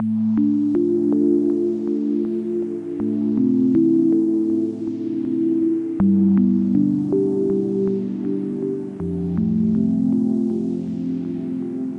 Tag: 80 bpm Chill Out Loops Synth Loops 2.02 MB wav Key : A